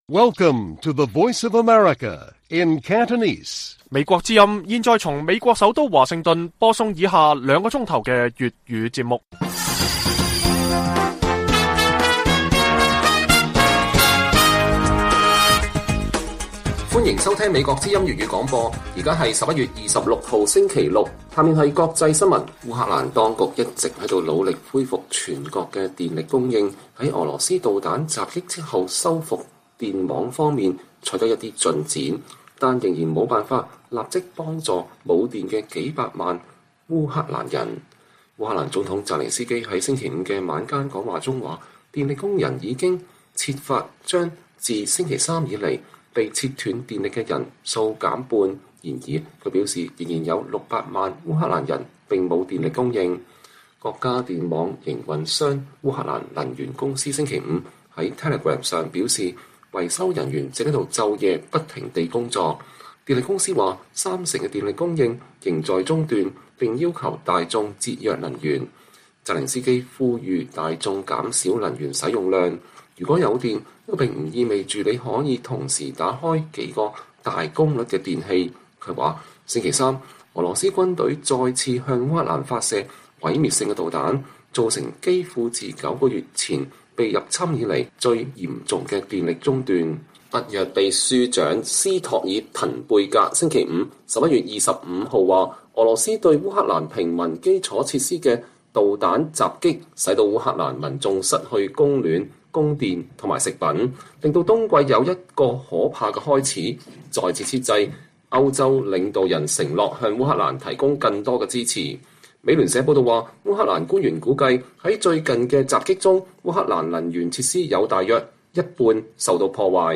粵語新聞 晚上9-10點 : 習近平外交復出秀吸引多國領導人 但成效有待觀察